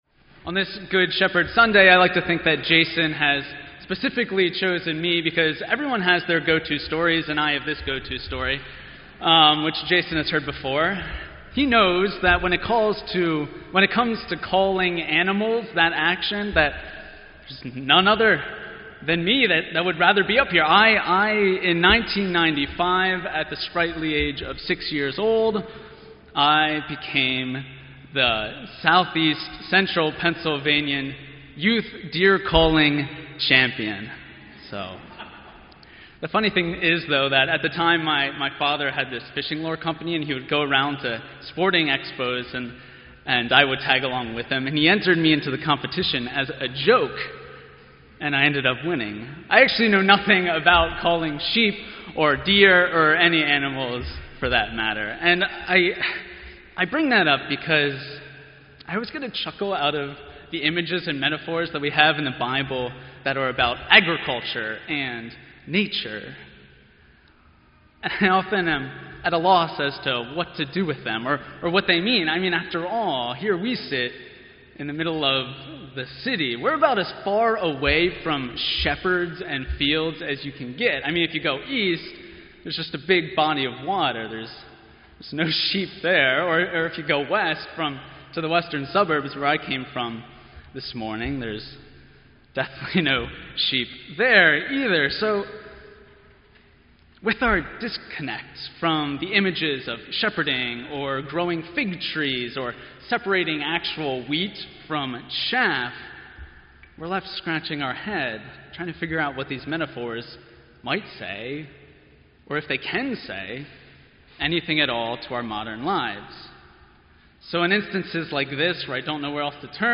Sermon_4_17_16.mp3